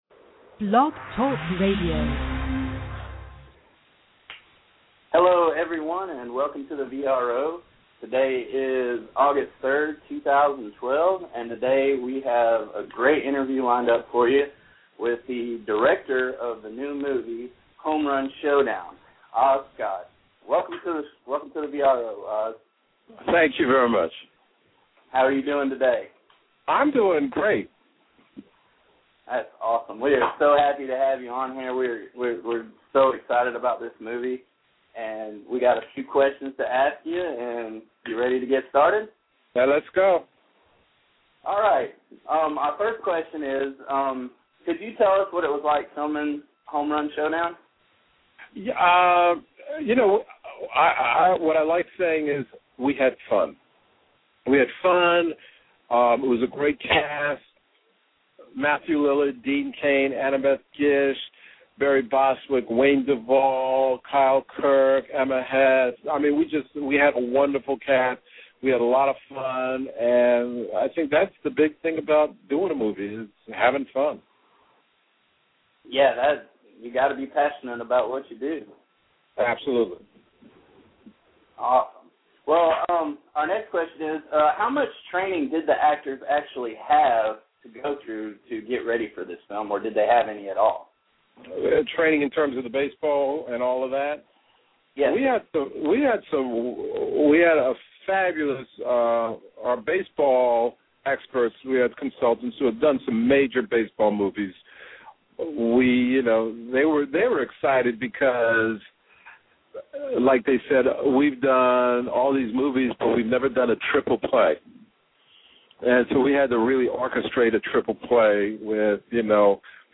Oz Scott - Interview